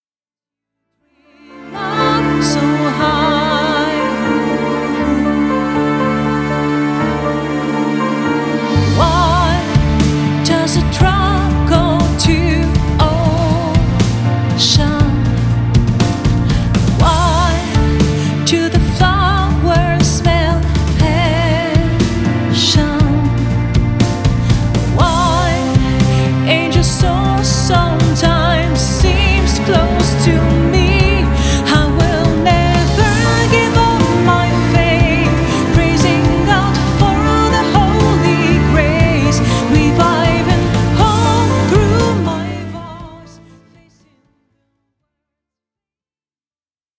Dans un style pop saupoudré de musique du monde